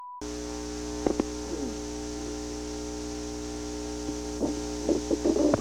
• President Richard M. Nixon
Location: White House Telephone
The President talked with the White House operator.